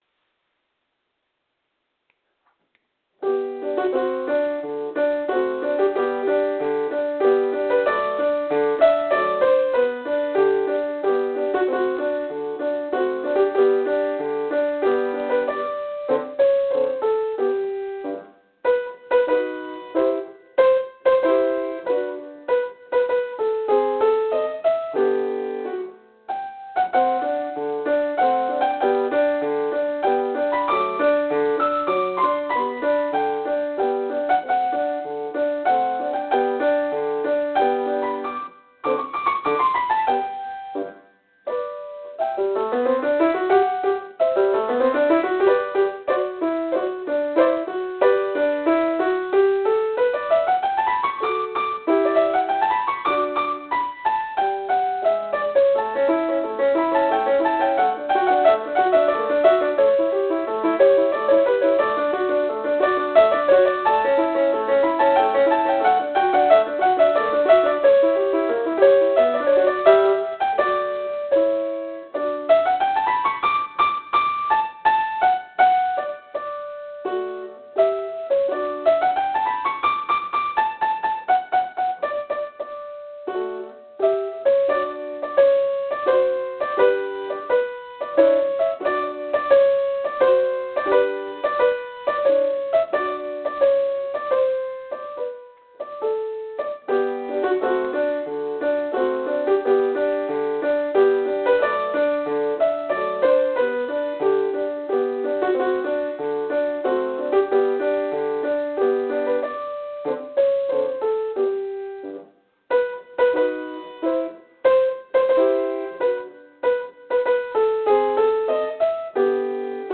趁着节日热闹，献上钢琴独奏一首，其中节奏上有点问题，顾不上了。